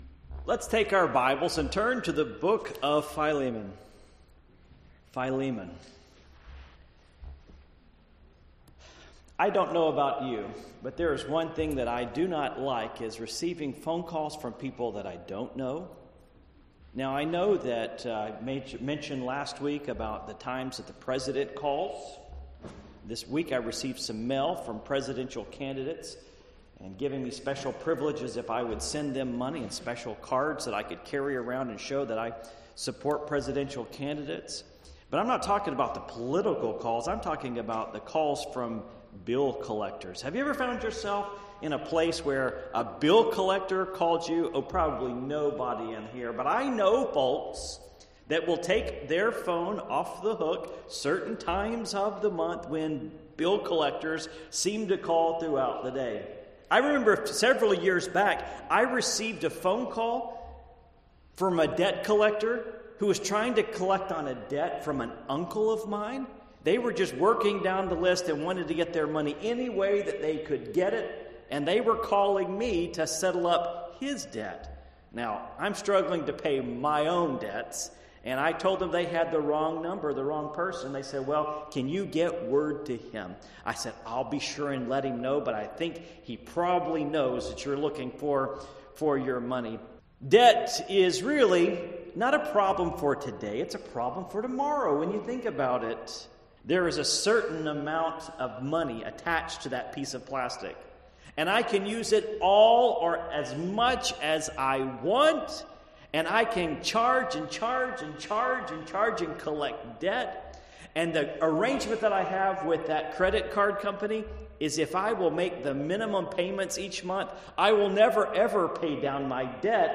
Passage: Philemon 1:15-19 Service Type: Morning Worship